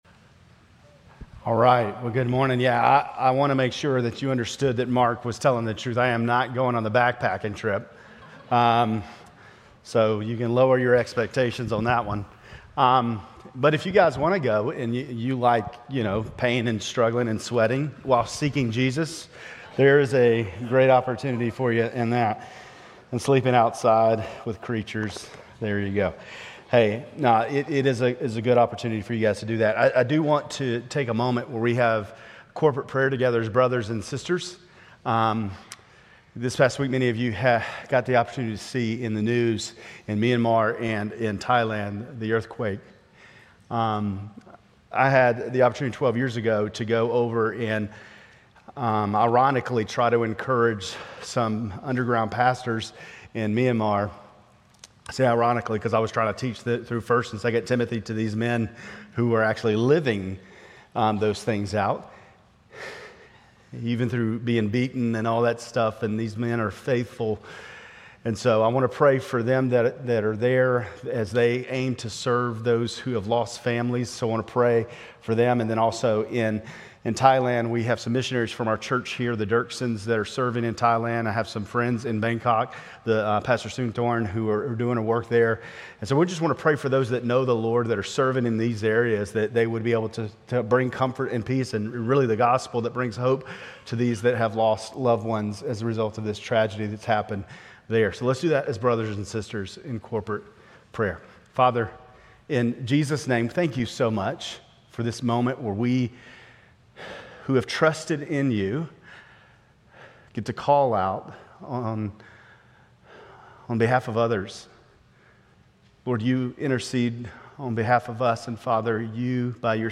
Grace Community Church Lindale Campus Sermons 3_30 Lindale Campus Mar 31 2025 | 00:30:35 1x 00:00 / 00:30:35 Subscribe Share RSS Feed Share Link Embed